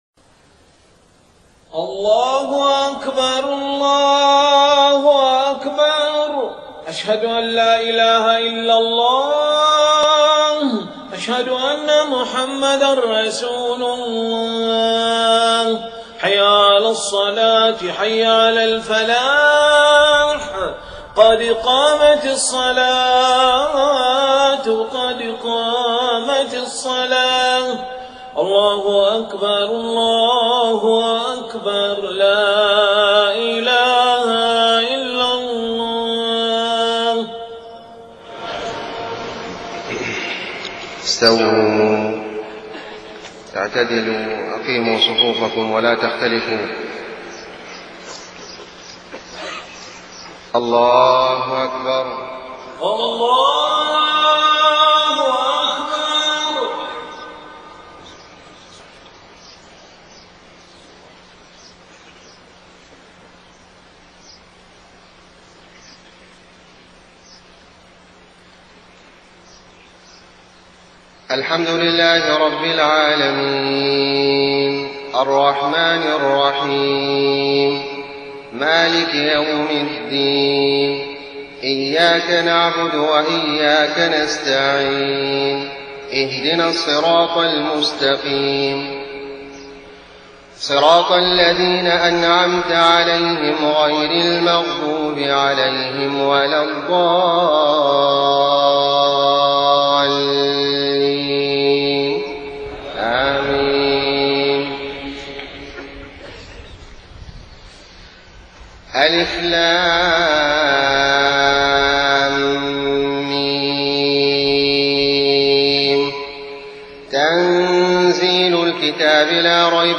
صلاة الفجر 5 محرم 1430هـ سورتي السجدة و الانسان > 1430 🕋 > الفروض - تلاوات الحرمين